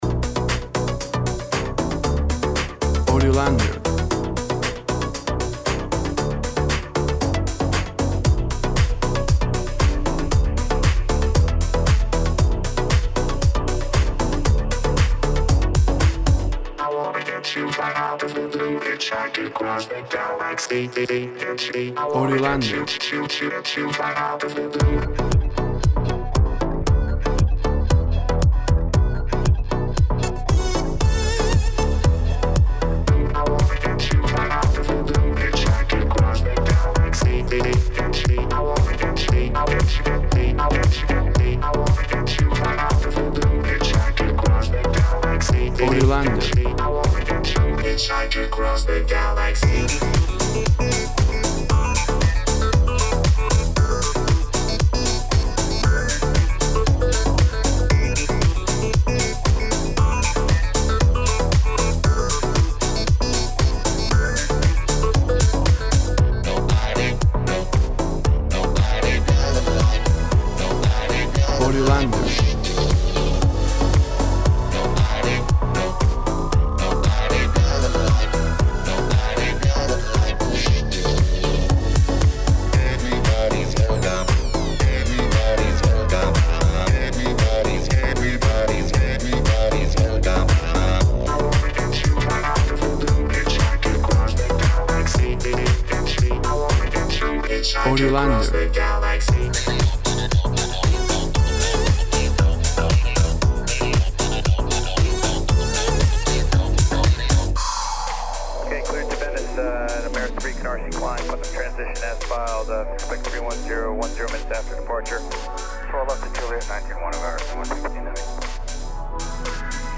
Tempo (BPM) 117